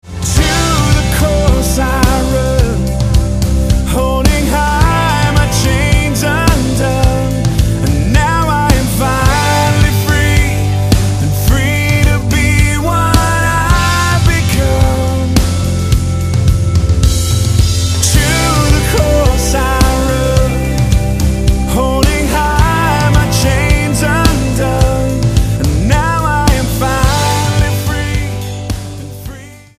STYLE: Rock